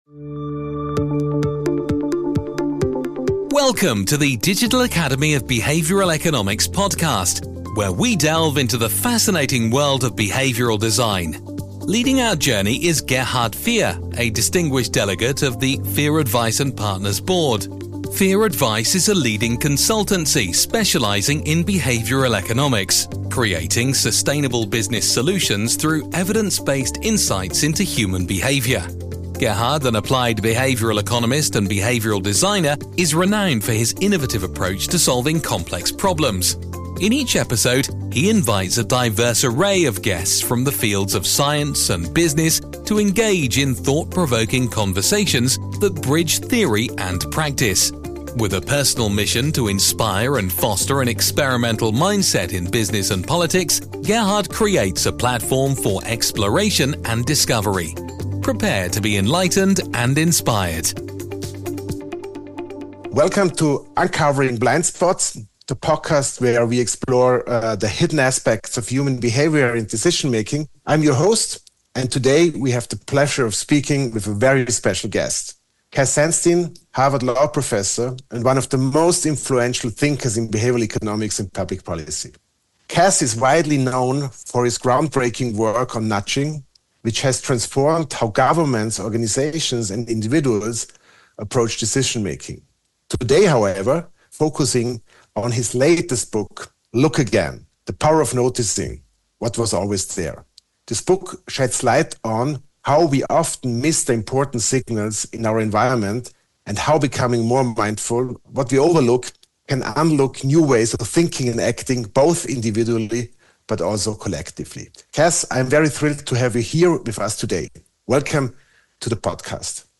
In jeder Episode führt er Gespräche mit Experten aus Wissenschaft und Wirtschaft, die Theorie und Praxis verbinden.